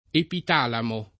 [ epit # lamo ]